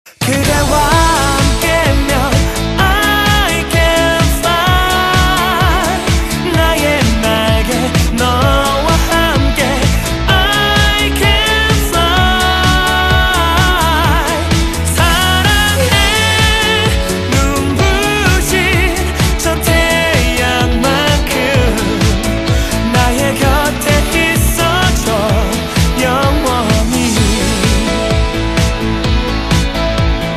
日韩歌曲